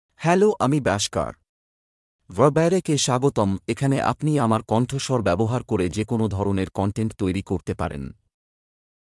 MaleBengali (India)
Bashkar is a male AI voice for Bengali (India).
Voice sample
Listen to Bashkar's male Bengali voice.
Bashkar delivers clear pronunciation with authentic India Bengali intonation, making your content sound professionally produced.